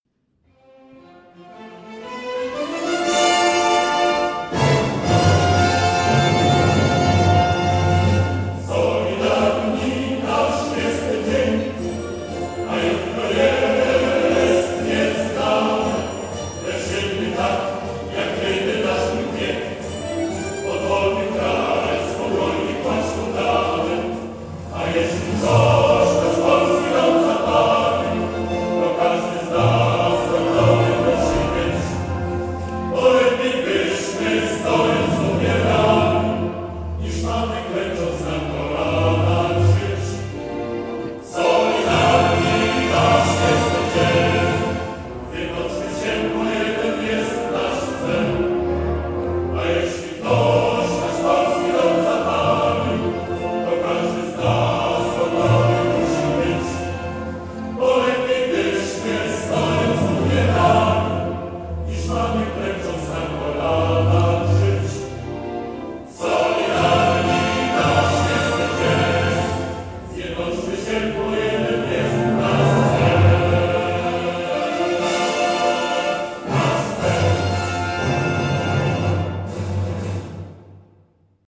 Zagrana, rzecz jasna, z płyty, bo w dobie Covidu-19 śpiewać chóralnie nie można.
Taka pieśń na wejście sztandarów: Hymn Solidarności